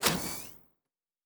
Door 6 Open.wav